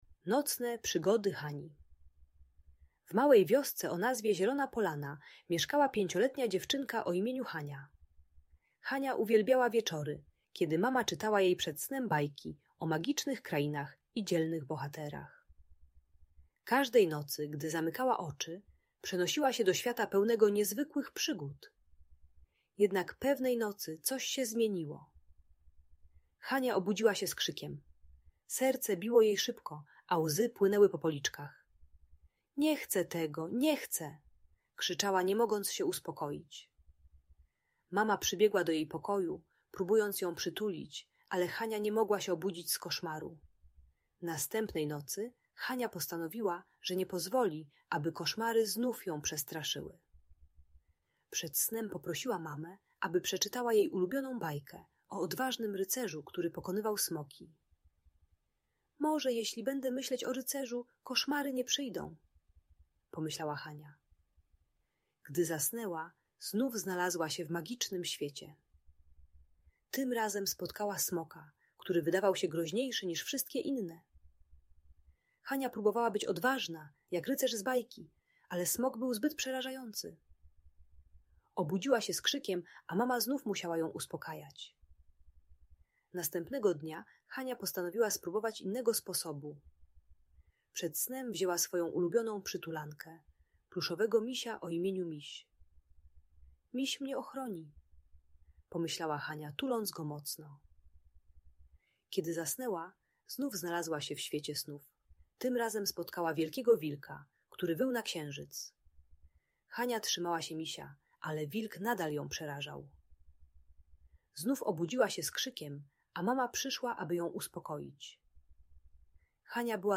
Nocne Przygody Hani - Magiczna Opowieść - Audiobajka dla dzieci